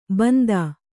♪ bandā